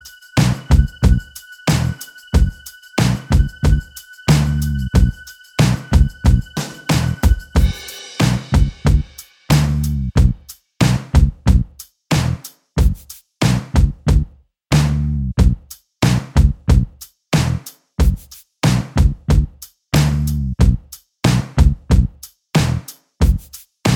Minus All Guitars Indie / Alternative 2:44 Buy £1.50